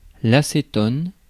Ääntäminen
Synonyymit propanone diméthylcétone Ääntäminen France: IPA: [a.se.tɔn] Haettu sana löytyi näillä lähdekielillä: ranska Käännös Konteksti Substantiivit 1. ацетон {m} kemia Muut/tuntemattomat 2. ацето́н {m} Suku: f .